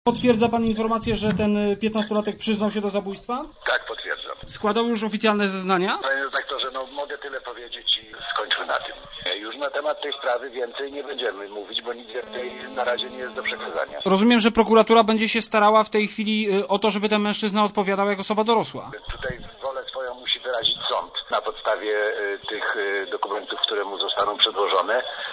Komentator żabim głosem komentuje 6tergedię.